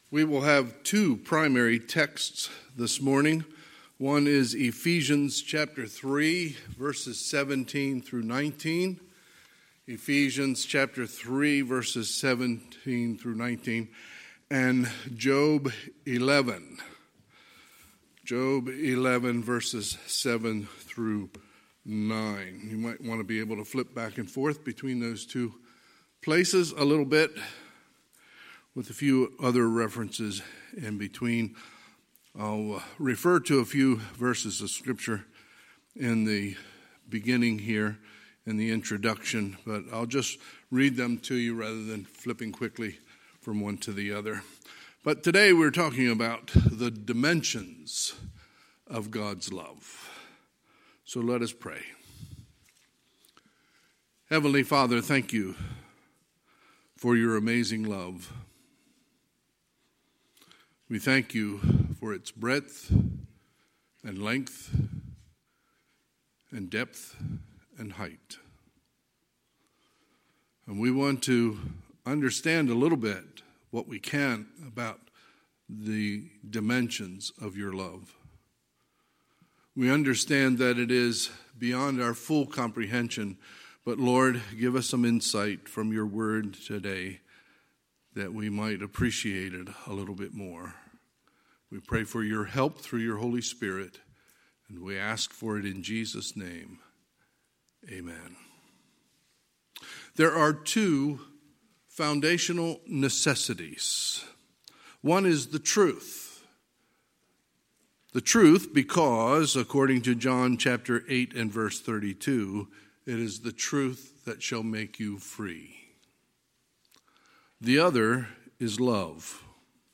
Sunday, April 11, 2021 – Sunday AM
Sermons